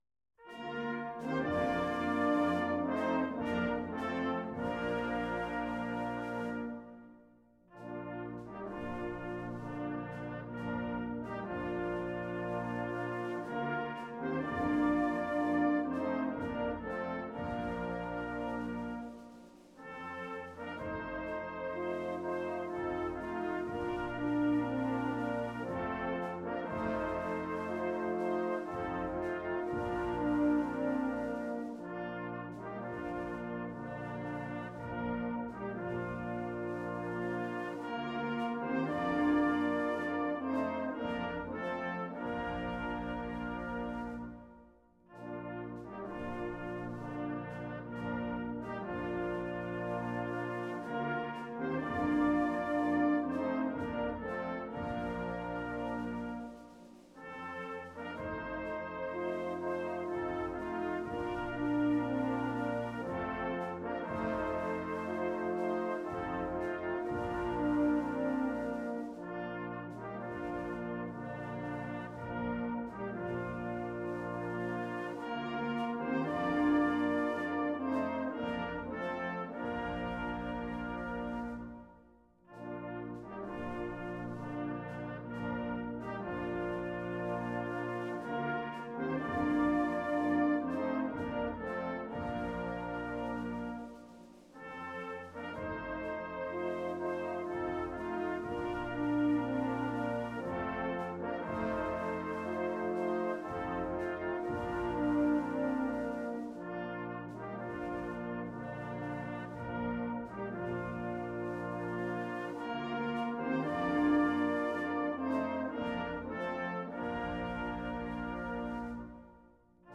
Key: B♭
Tempo: 66